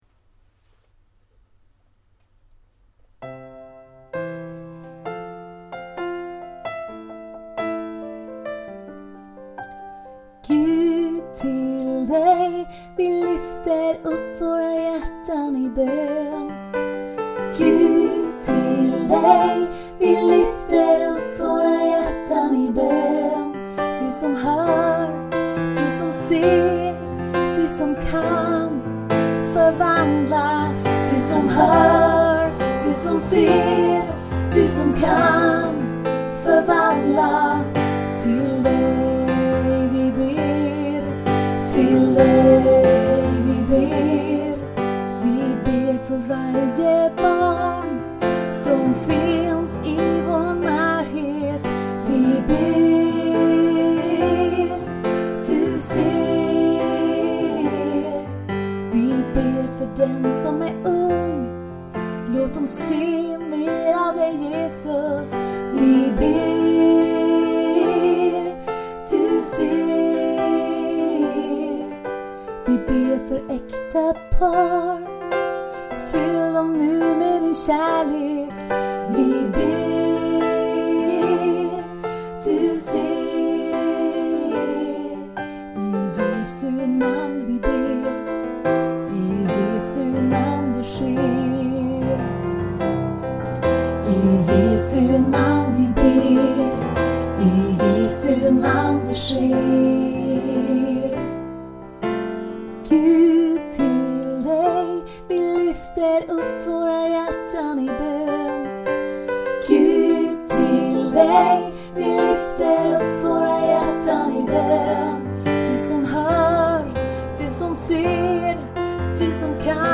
En förböns sång, där en försångare sjunger först och församlingen stämmer in. Kan användas i kyrkans förbön, och i bönesamlingar och under böneveckor eller för egen bön hemma.